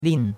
lin4.mp3